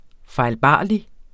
Udtale [ fɑjlˈbɑˀli ]